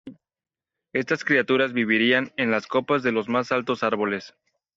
Pronounced as (IPA) /ˈkopas/